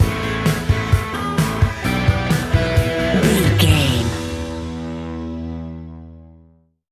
Ionian/Major
E♭
blues rock
distortion